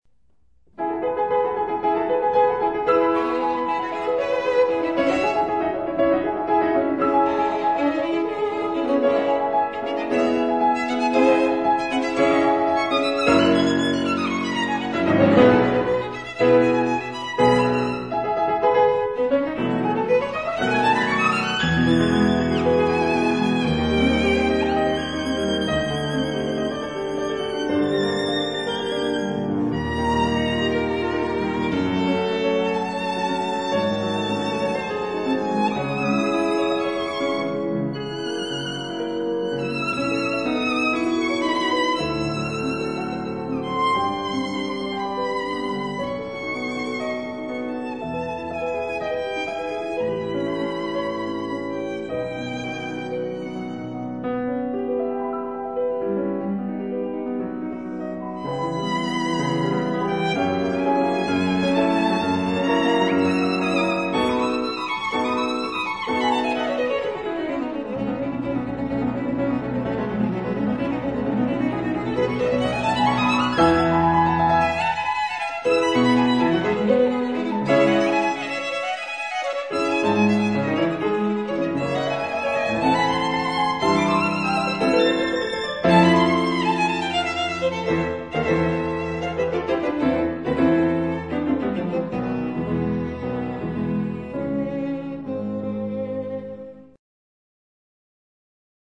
Con fuoco